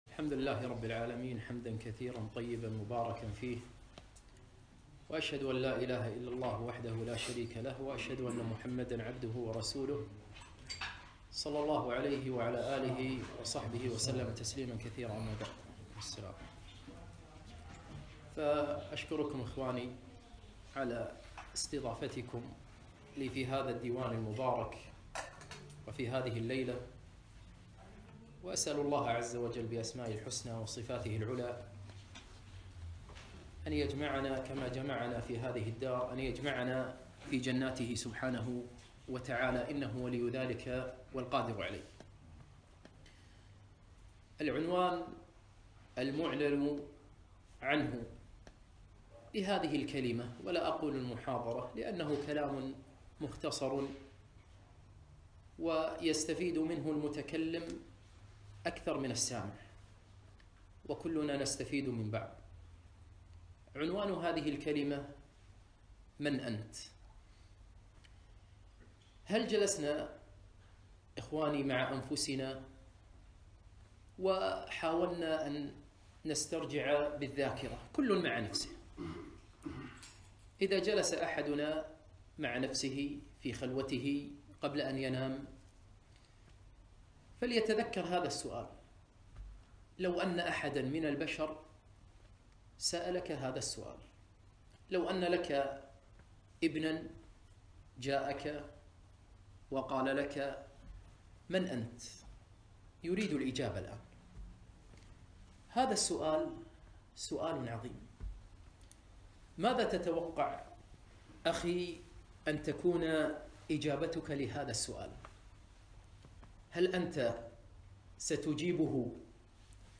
محاضرة - من أنت ؟!